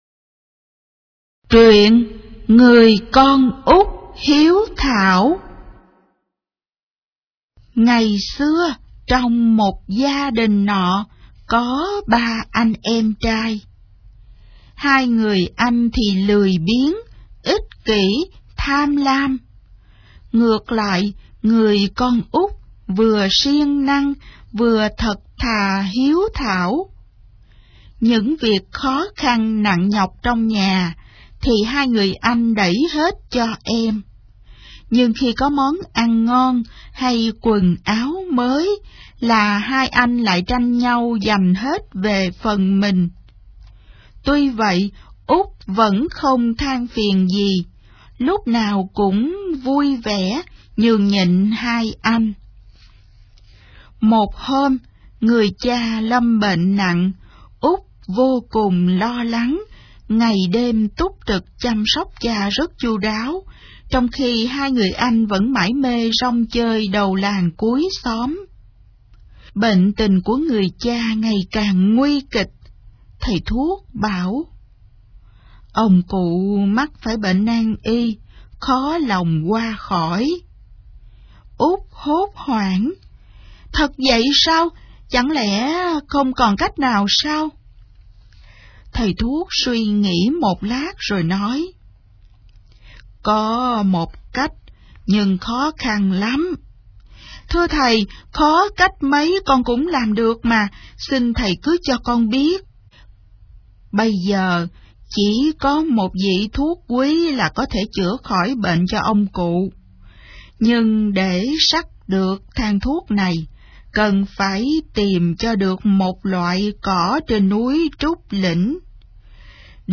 Sách nói | Em Bé Thông Minh